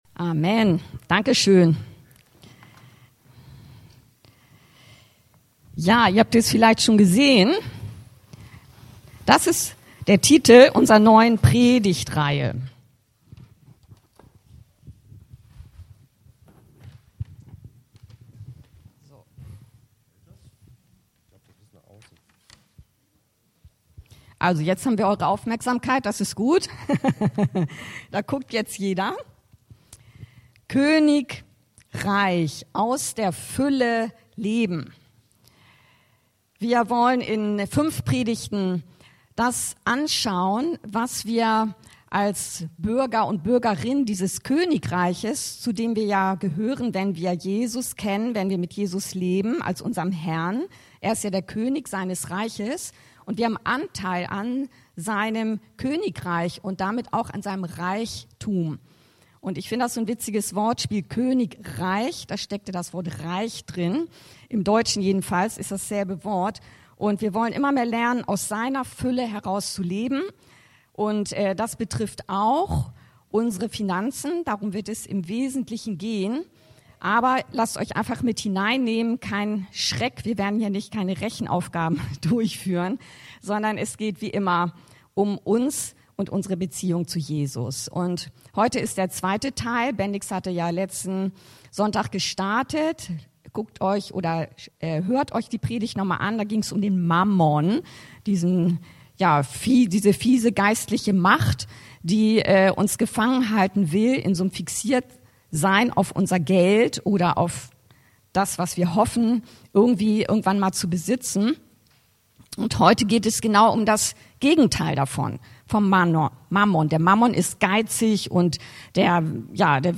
Predigtreihe "KönigReich" 2